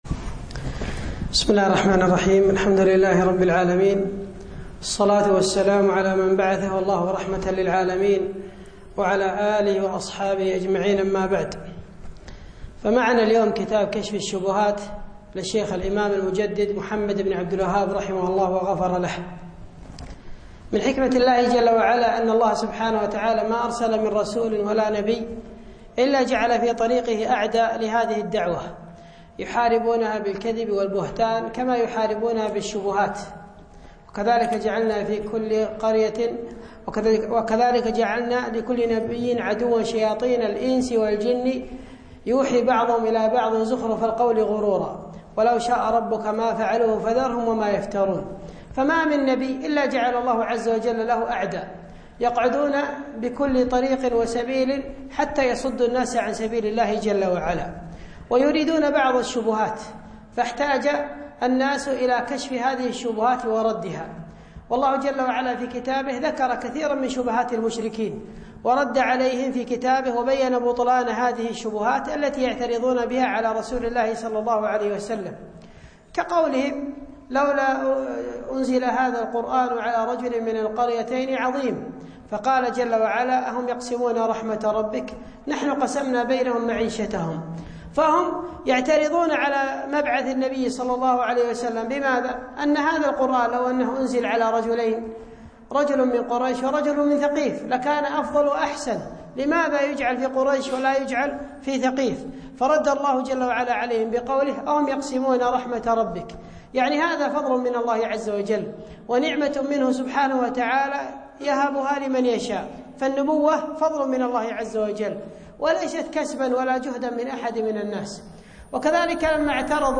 الدرس الأول